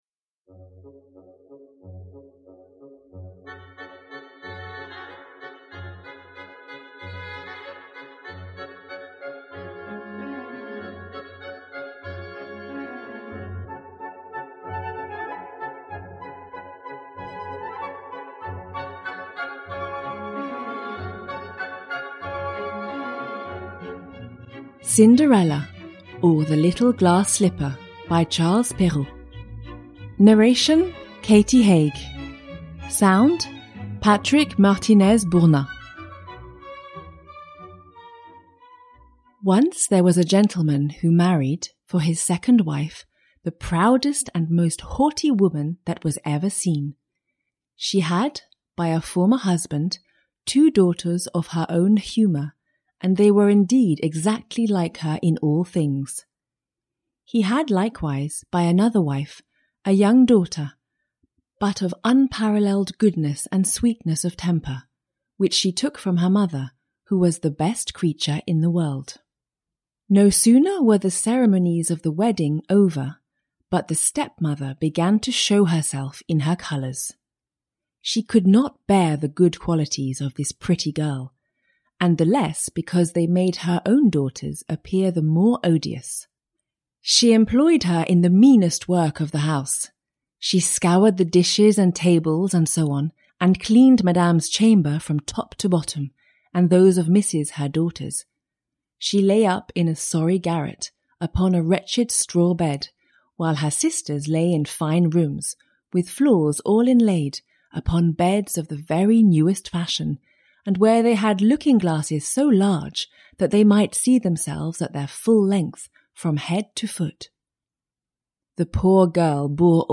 These stories include: Cinderella by Charles Perrault, Beauty and the Beast, Blue Beard, Snow White and Rose Red, Snowdrop and the Seven Dwarves, The Fairies, The Master Cat or Puss in Boots, The Sleeping Beauty in the Woods, The Frog Prince, The Princess and the Pea by Hans Christian Andersen, The Toad, and Rapunzel. All are narrated in a warm and lively way for the best of enjoyment.